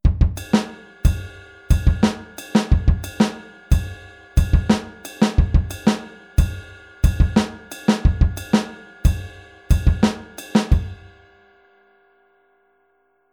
Rechte Hand wieder auf dem Kopfbecken